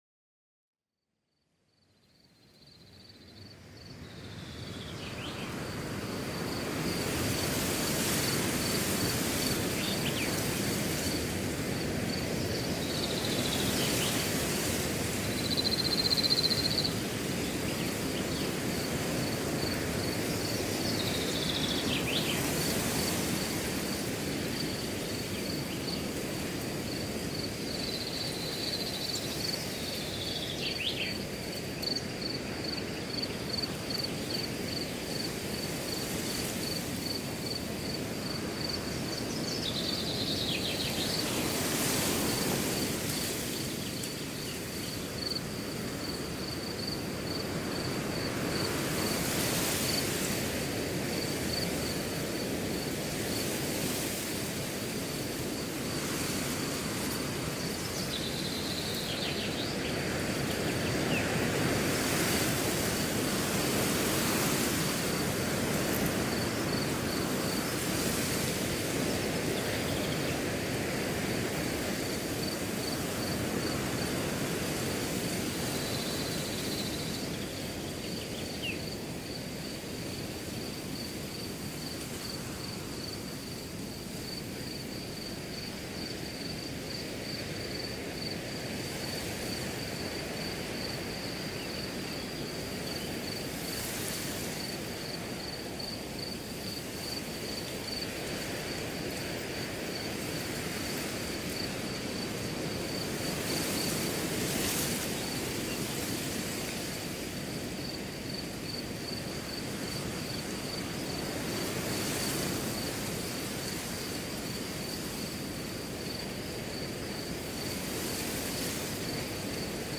SCHLAFLOSIGKEITS-LÖSUNG: Gras-Flüstern mit geheimnisvollem Sound